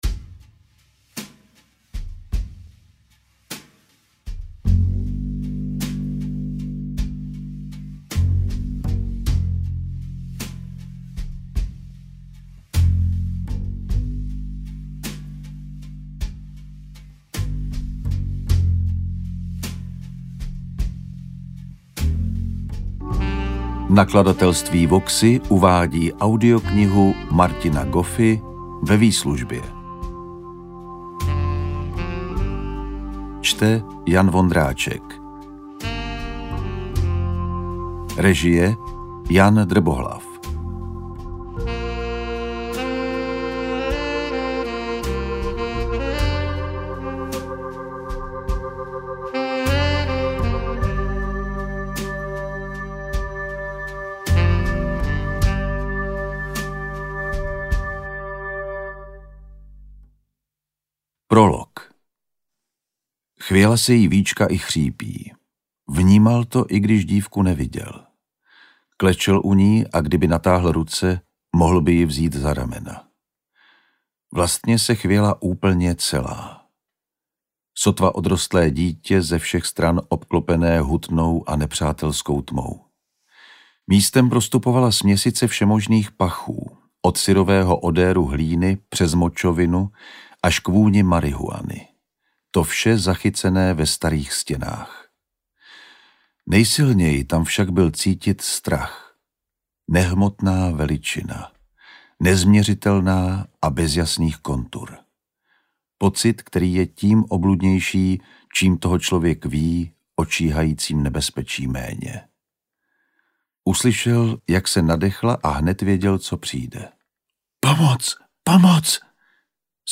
Interpret:  Jan Vondráček